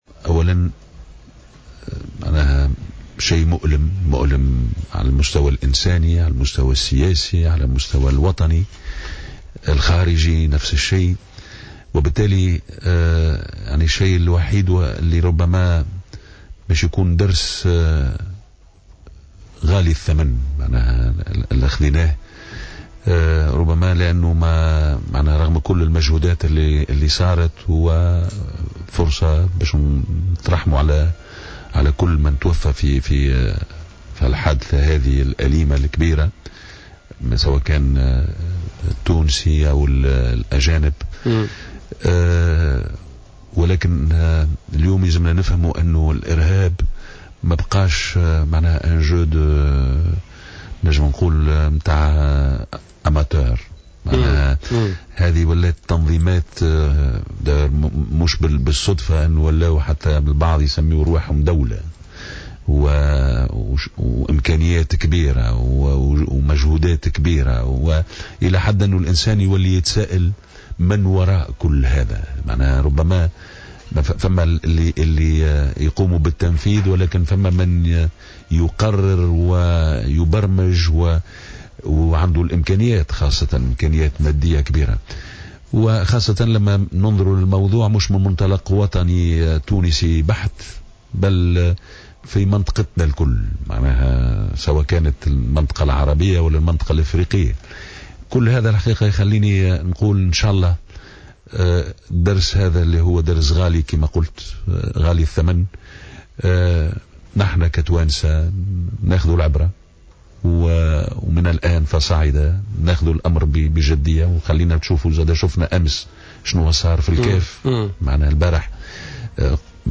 اعتبر رئيس حزب المبادرة الوطنية الدستورية كمال مرجان، ضيف بوليتيكا اليوم الاثنين أنه من الصعب إيجاد الكلمات والألفاظ التي تعبر عن الشعور بعد ما حدث في متحف باردو، واصفا الحادث بالمؤلم على المستوى الانساني .